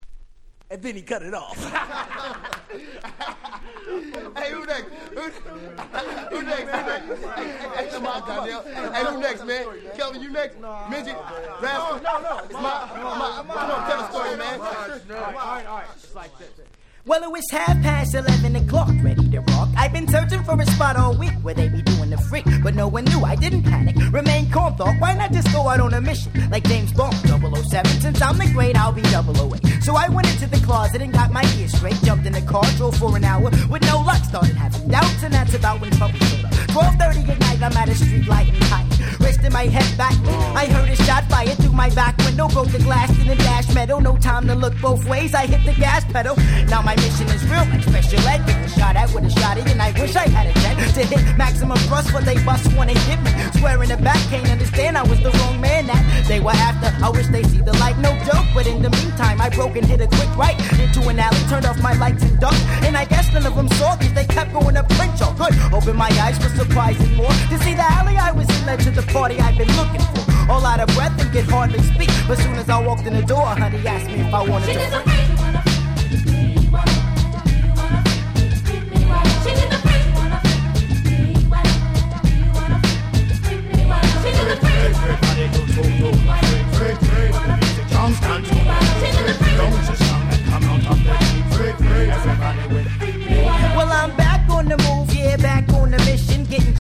94' Nice West Coast Hip Hop !!
両面共にキャッチーでFunkyなウエッサイナンバー！！